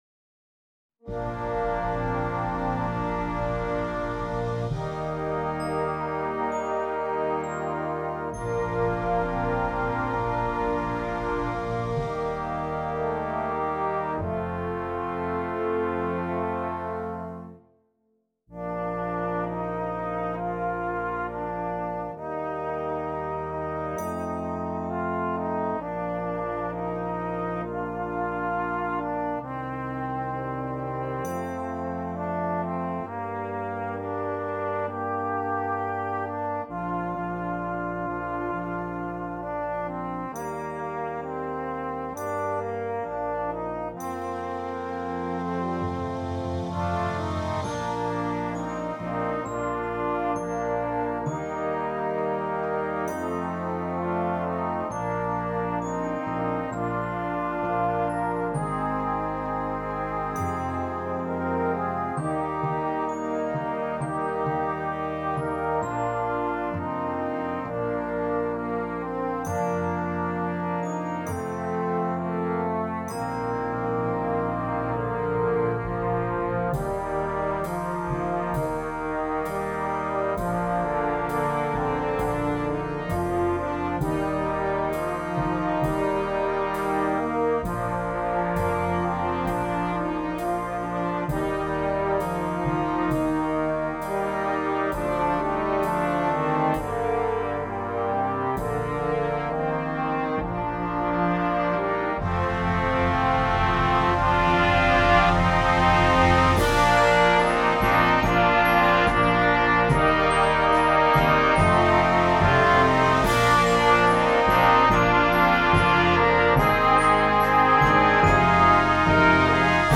Gattung: Für Blechbläserensemble
Besetzung: Ensemblemusik Blechbläserensemble